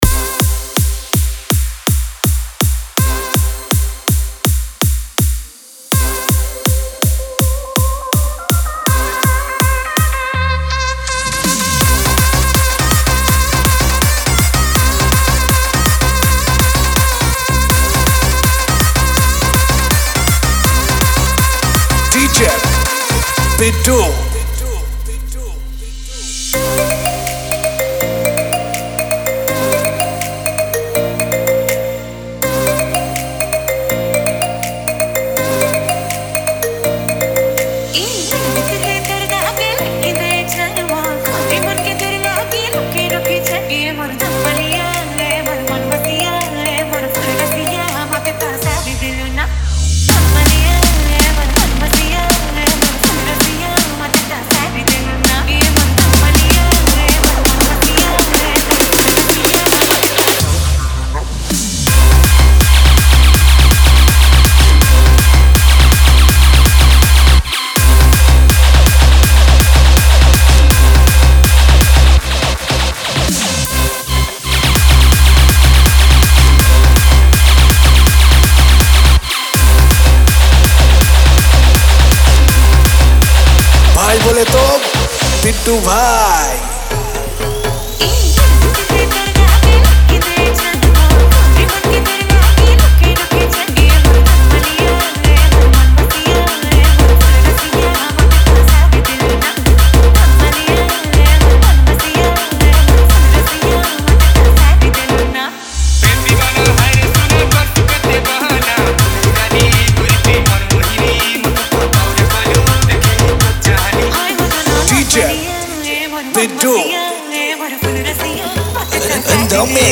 • Category: Sambalpuri Dj Remix Songs